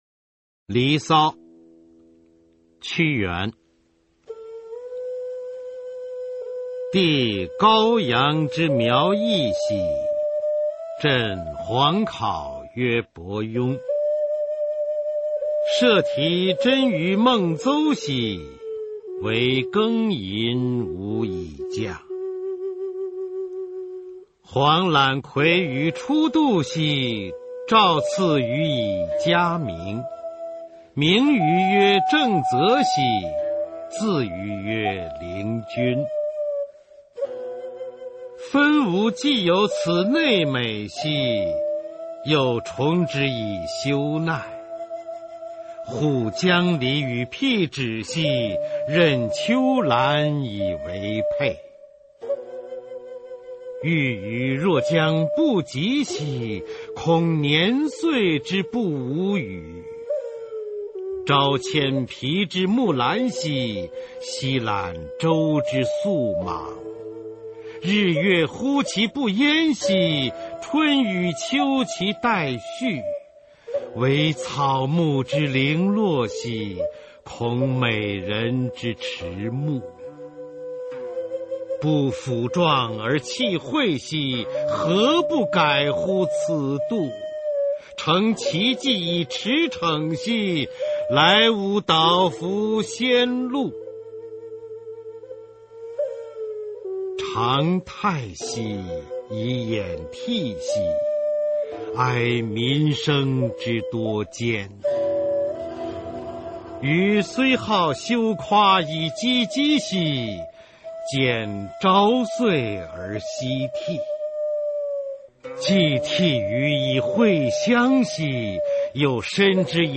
[先秦诗词诵读]屈原-离骚（节选） 朗诵